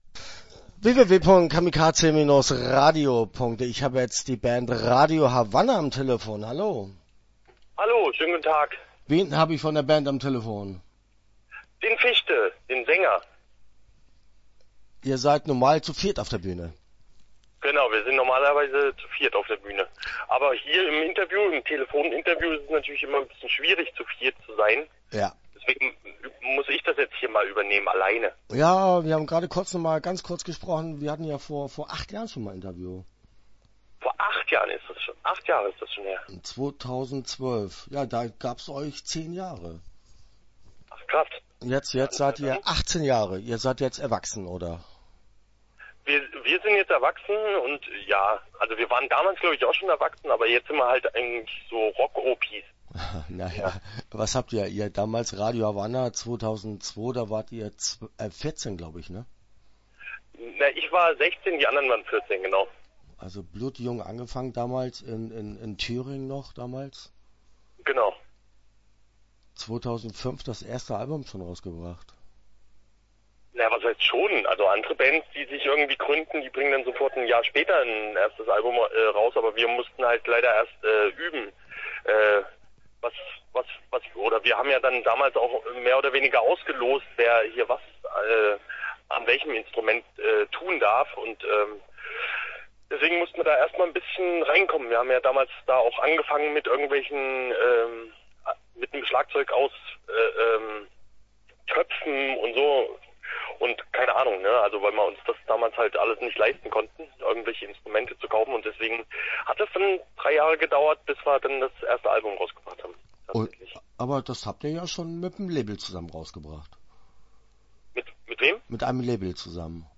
Start » Interviews » RADIO HAVANNA